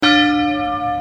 A chaque déplacement de la cloche correspondra un son de cloche.
dong12.mp3